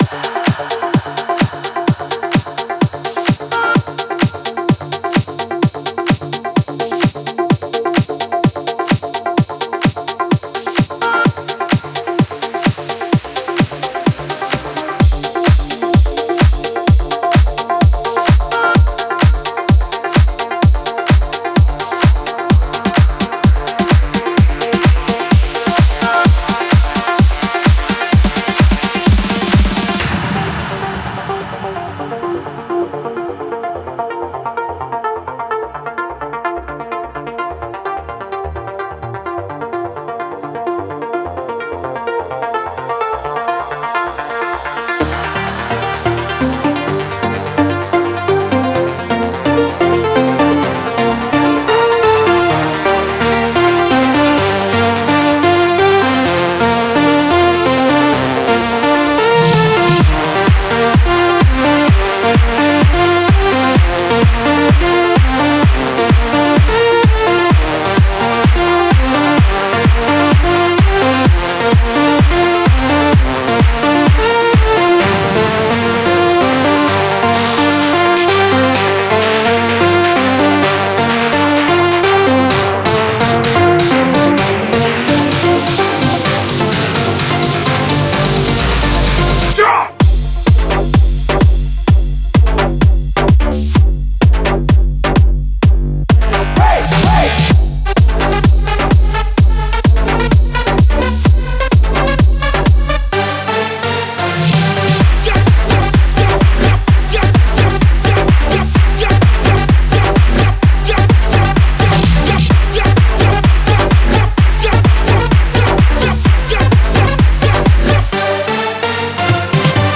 electro house banger